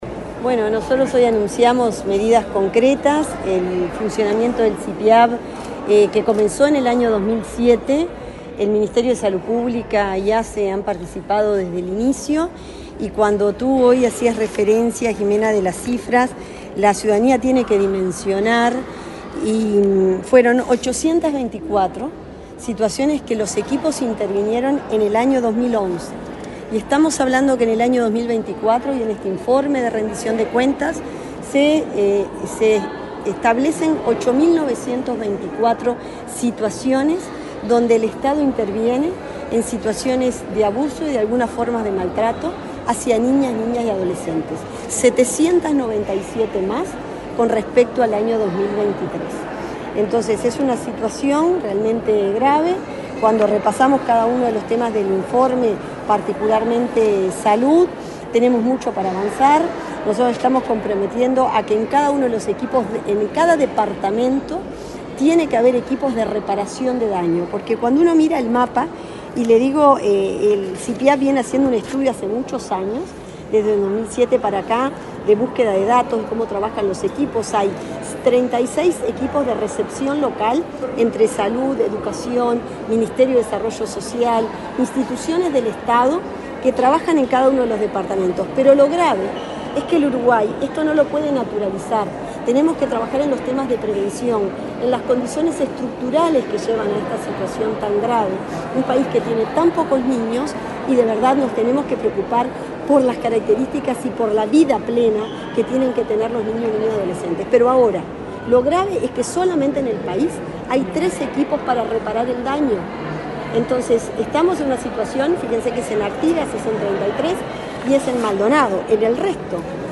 Declaraciones de la ministra de Salud Pública, Cristina Lustemberg
La ministra de Salud Pública, Cristina Lustemberg, dialogó con la prensa, luego de participar de la presentación del informe anual de gestión del